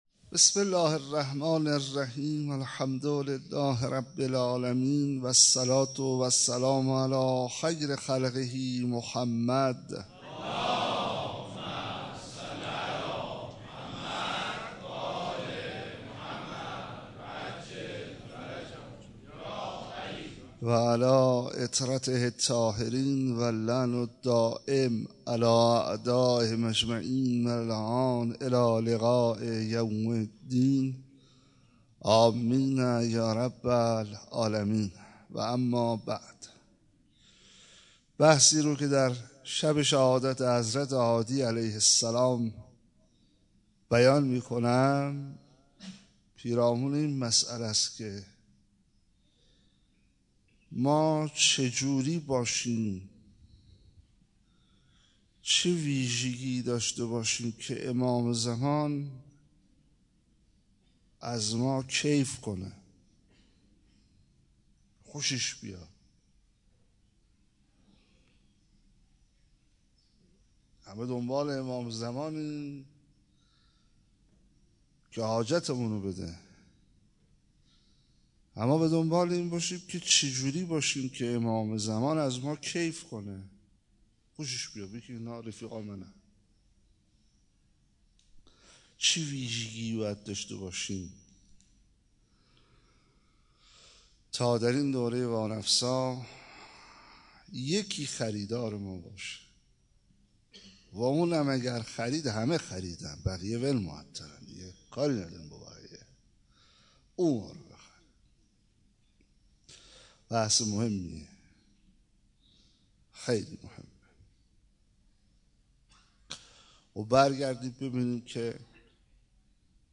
17 اسفند 97 - مسجد حضرت امیر - اسباب رضایت امام عصر (عج)